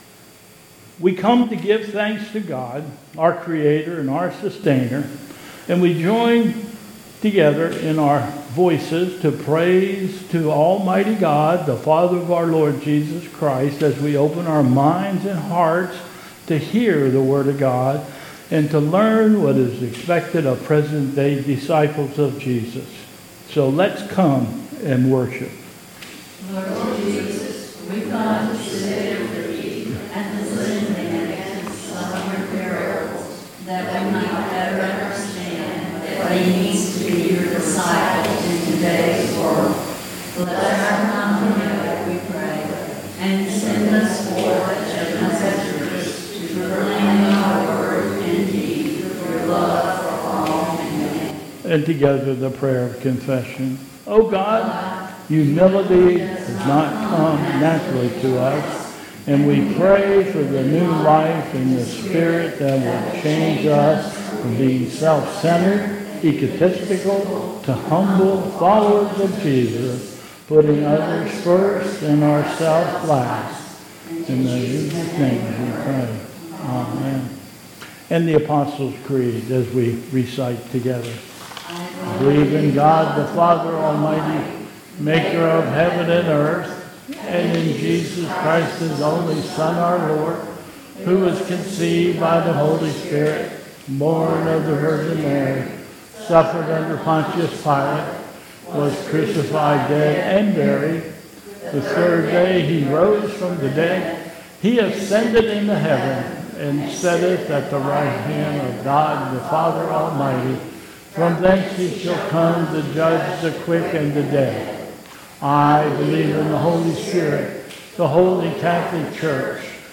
Call to Worship, Affirmation of Faith and Gloria Patria